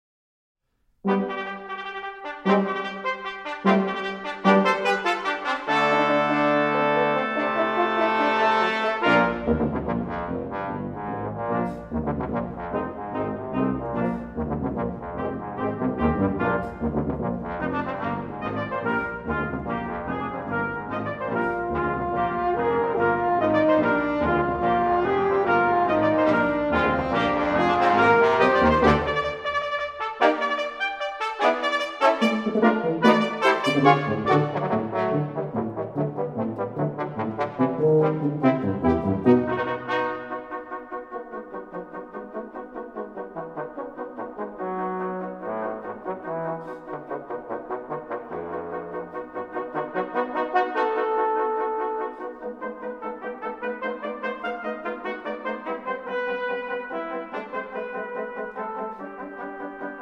Trumpet
Horn
Trombone
Euphonium